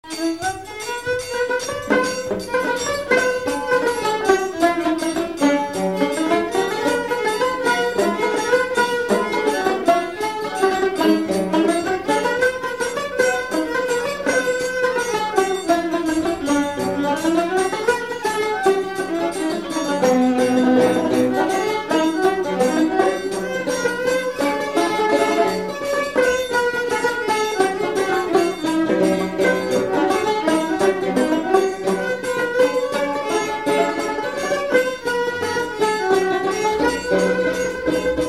Séga
Résumé Instrumental Fonction d'après l'analyste danse : séga
Catégorie Pièce musicale inédite